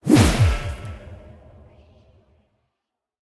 Minecraft Version Minecraft Version 1.21.5 Latest Release | Latest Snapshot 1.21.5 / assets / minecraft / sounds / block / trial_spawner / ominous_activate.ogg Compare With Compare With Latest Release | Latest Snapshot
ominous_activate.ogg